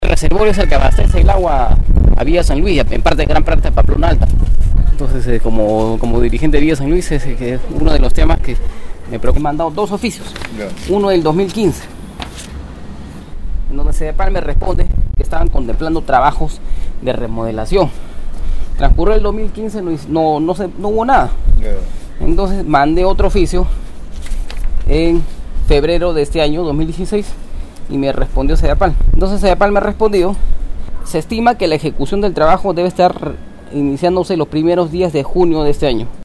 En una conversación con radio Stereo Villa